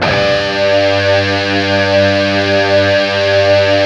LEAD G 1 LP.wav